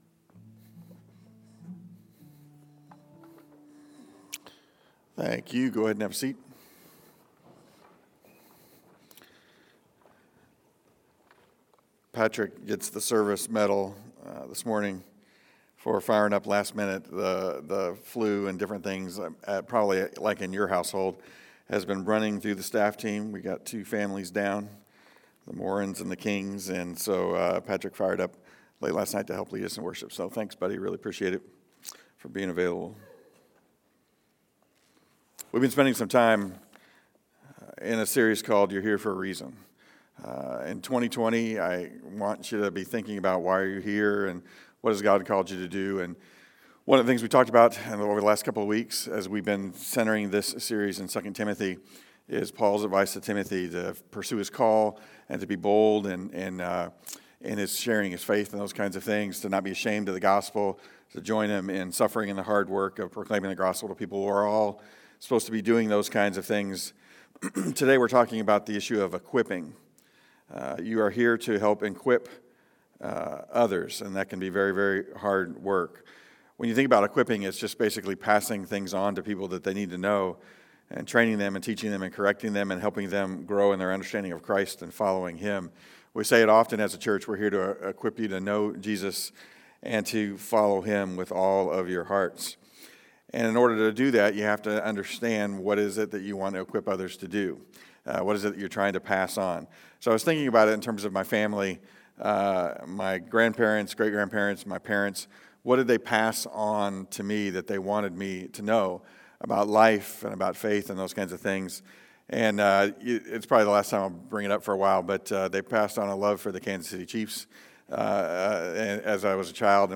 2 Timothy 2:1-13 sermon focuses on equipping people for ministry and enduring trials.